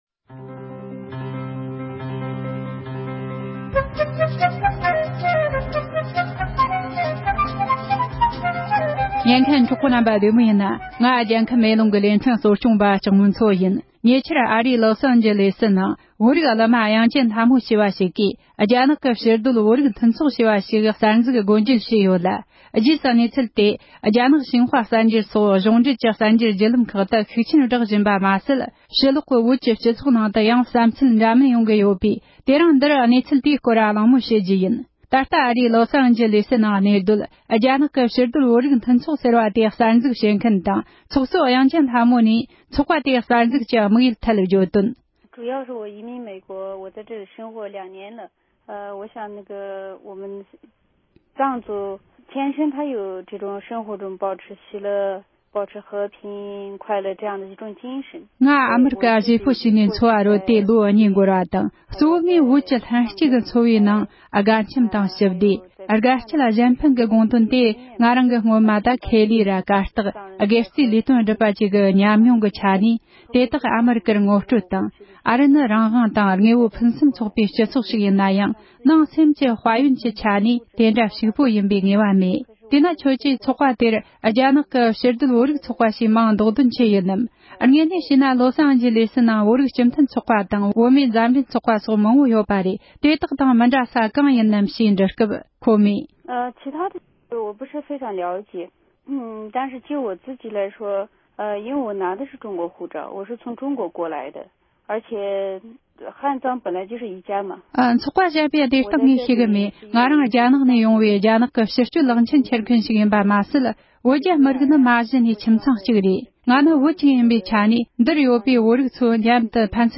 འབྲེལ་ཡོད་མི་སྣ་ཁ་ཤས་དང་མཉམ་དུ་གླེང་མོལ་ཞུས་པར་གསན་རོགས༎